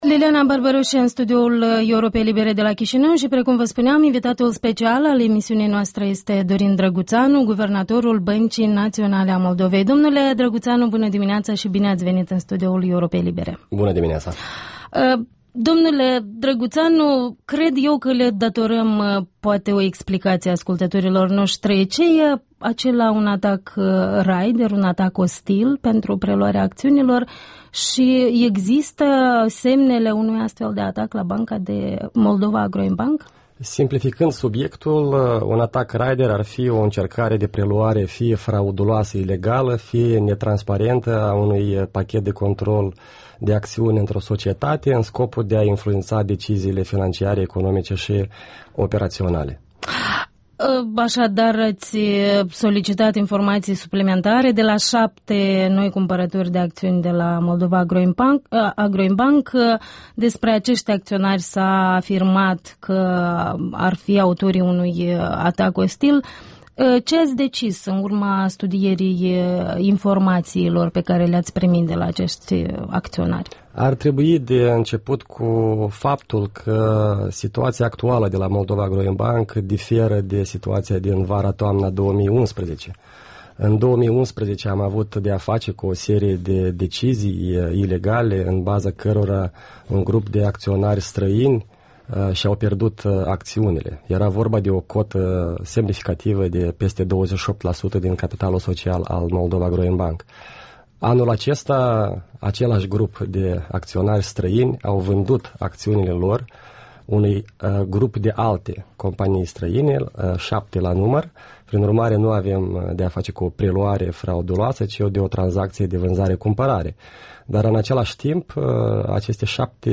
Interviul matinal la Europa Liberă: cu Guvernatorul BNM, Dorin Drăguţanu